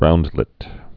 (roundlĭt)